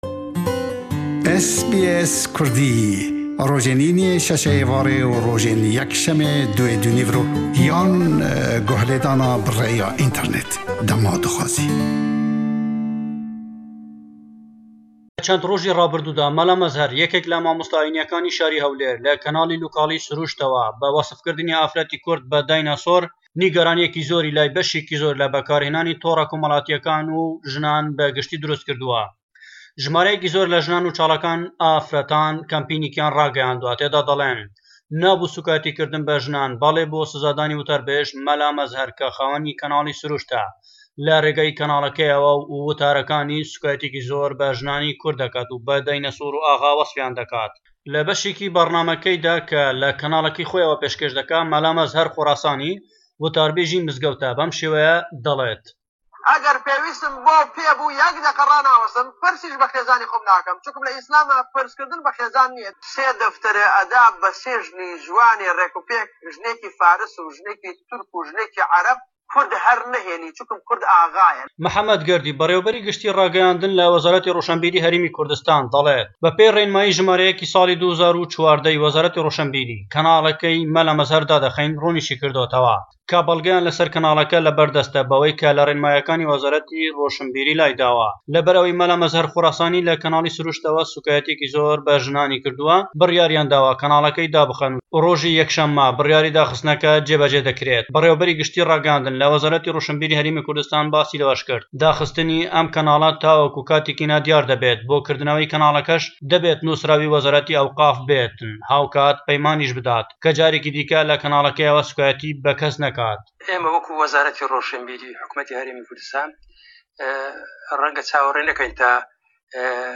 Raportî peyamnêrman